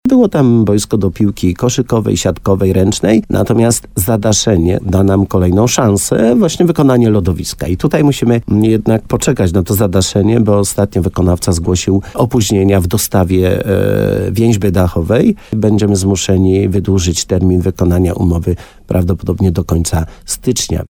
– mówi Leszek Skowron, wójt gminy Korzenna.